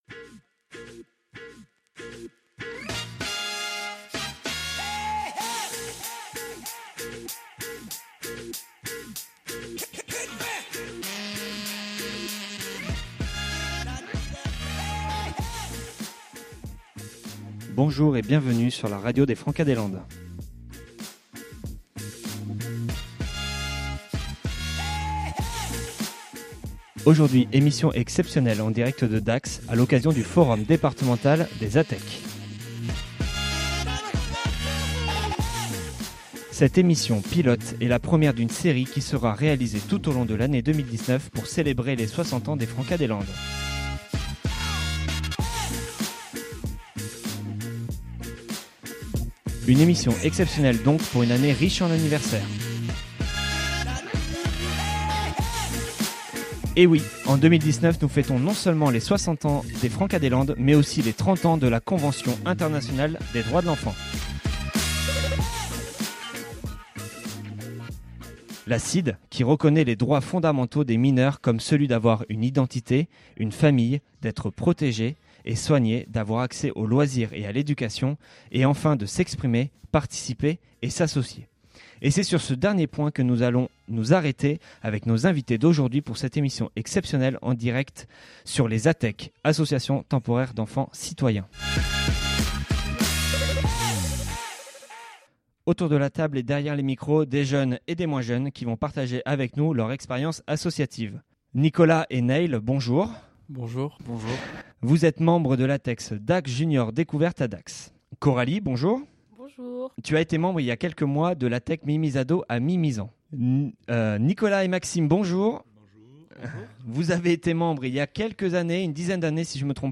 Émission exceptionnelle en direct de Dax à l’occasion du Forum Départemental des ATEC.